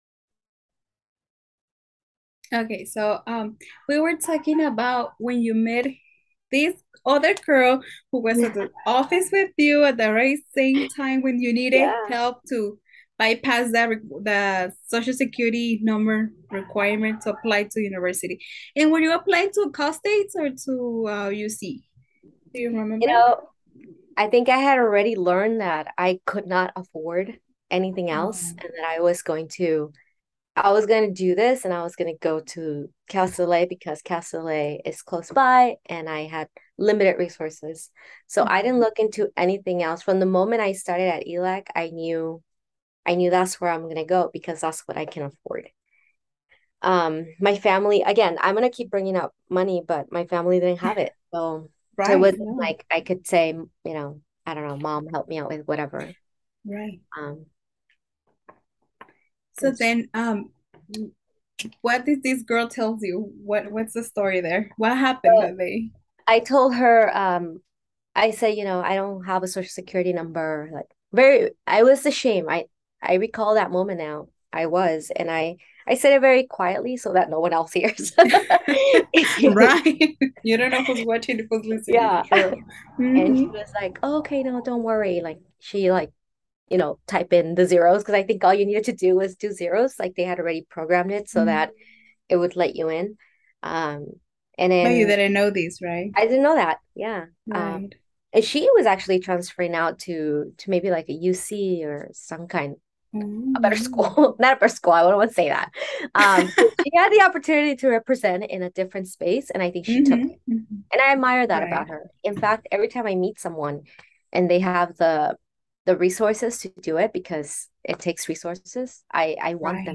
This is the second half of the interview.